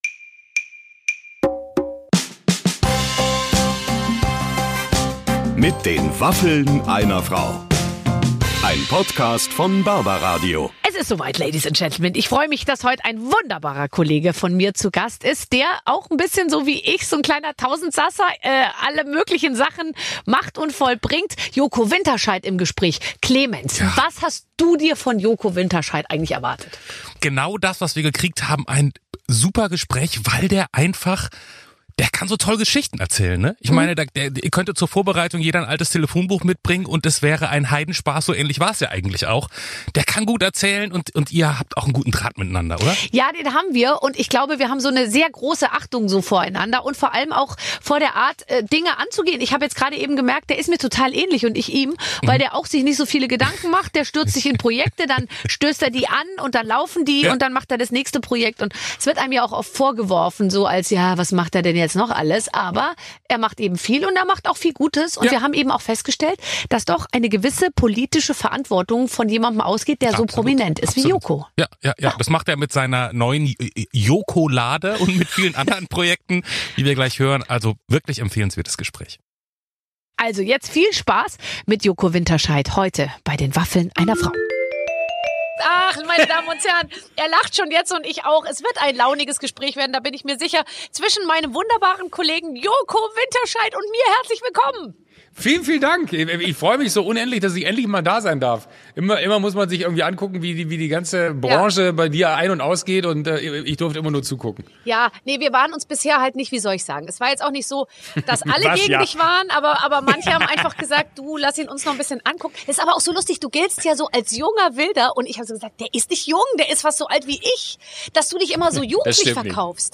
Joko Winterscheidt zu Gast bei Barbara Schöneberger! Im Gespräch mit Barbara Schöneberger verrät Joko Winterscheidt, wie er es schafft so viele Projekte gleichzeitig zu meistern.